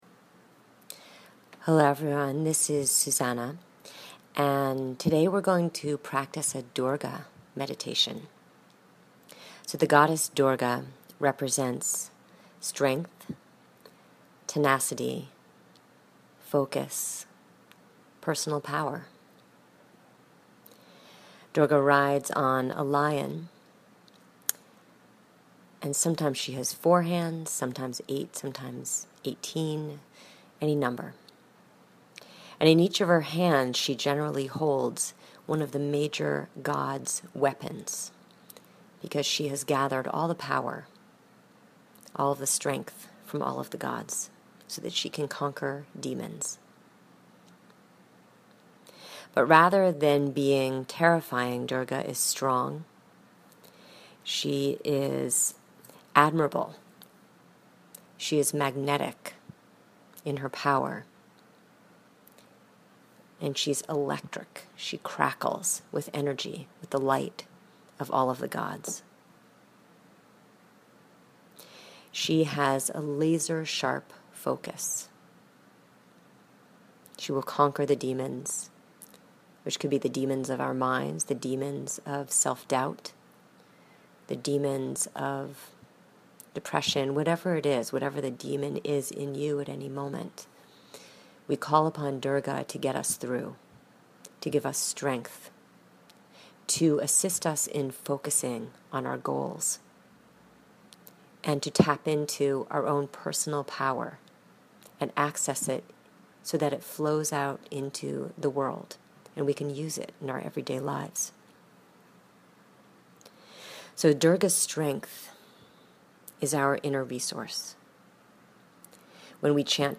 Week 3 Meditation: Durga